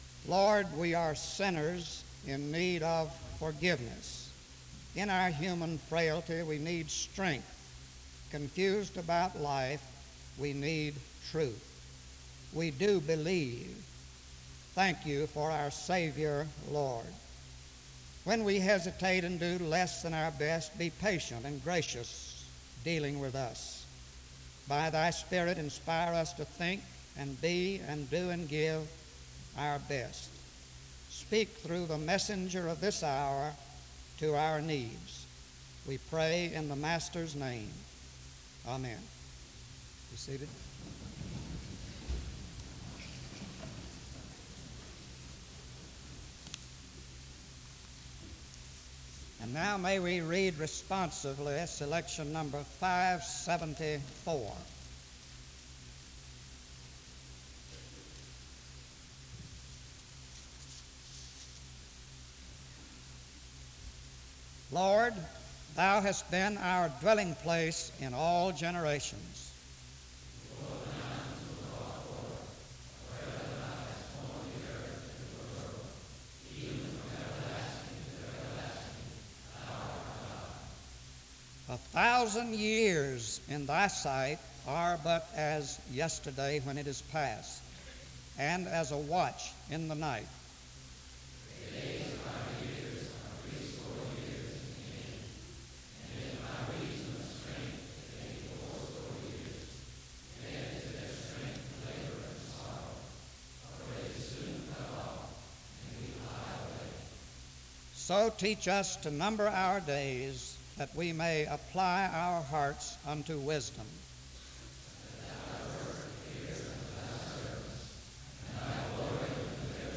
SEBTS Adams Lecture - Gardner C. Taylor February 23, 1977
Dans Collection: SEBTS Chapel and Special Event Recordings - 1970s SEBTS Chapel and Special Event Recordings La vignette Titre Date de téléchargement Visibilité actes SEBTS_Adams_Lecture_Gardner_C_Taylor_1977-02-23.wav 2026-02-12 Télécharger